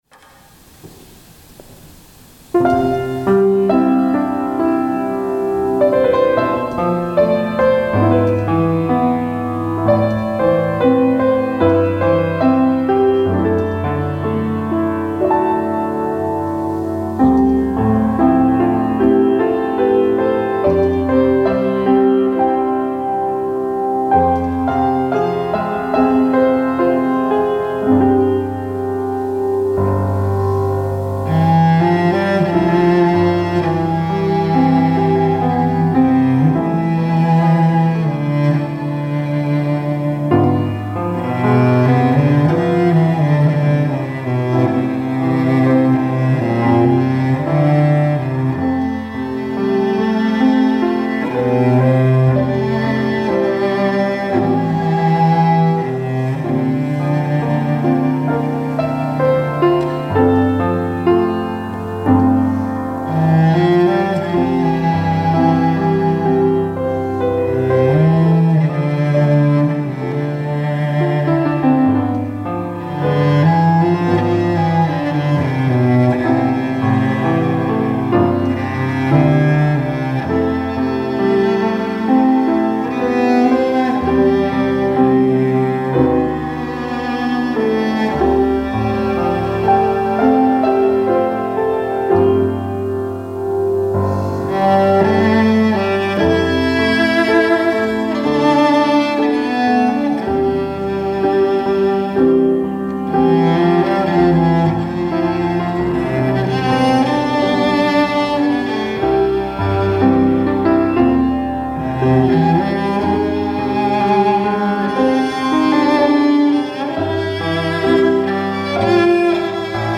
특송과 특주 - Amor Dei